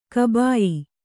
♪ kabāyi